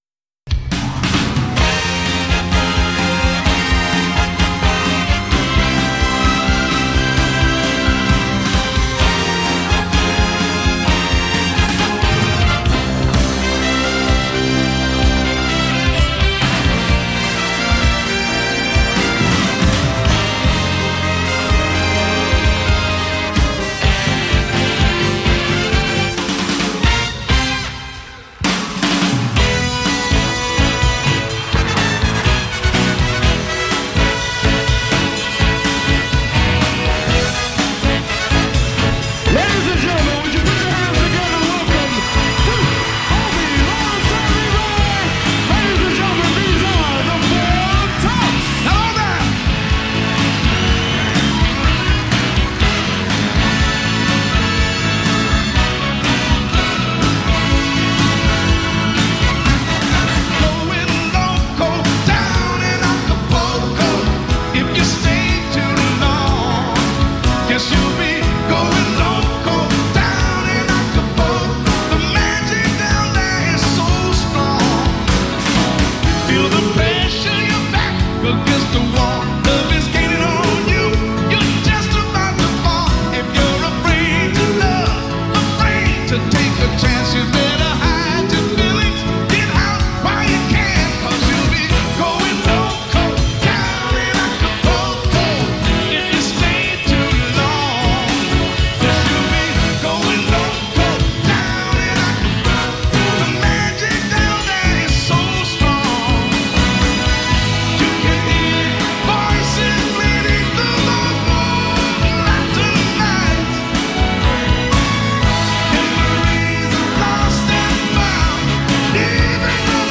Live at The Hammersmith Odeon, London